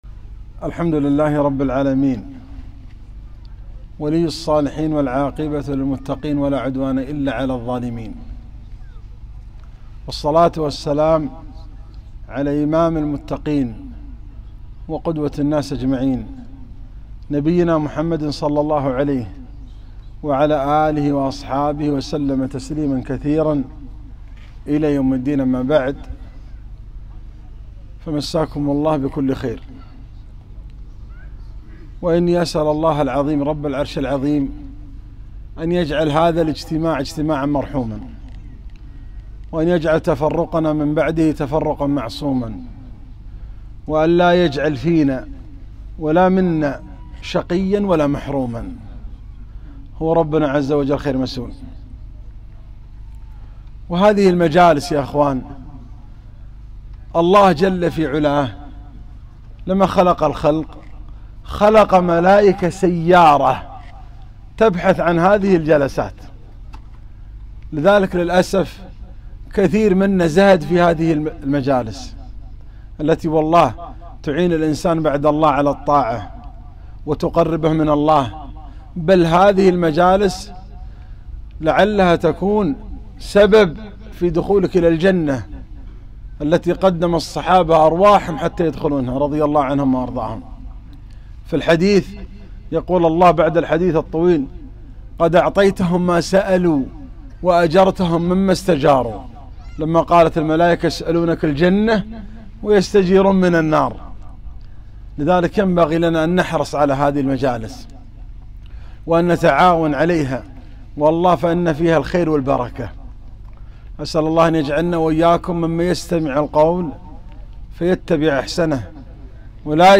محاضرة - ﴿والذين تبوءوا الدار والإيمان﴾